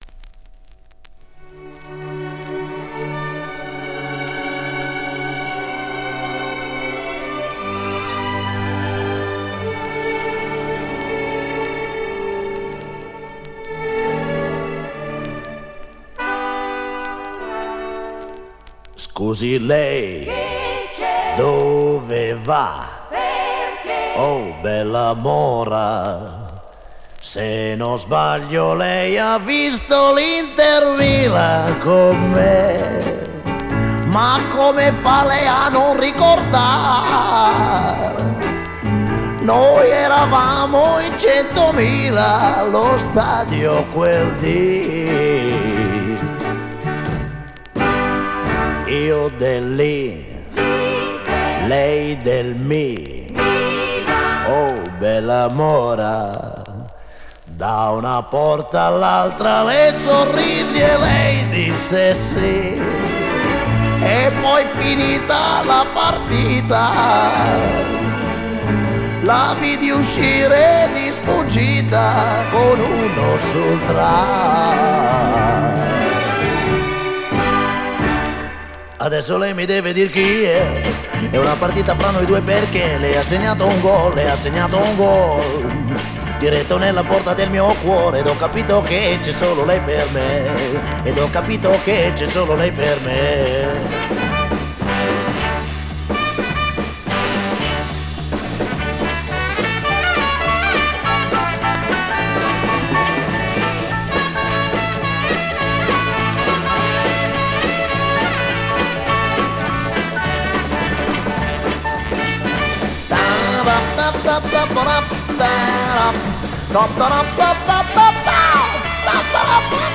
Жанр: Rock, Funk / Soul, Pop
Стиль: Rock & Roll, Chanson, Pop Rock, Ballad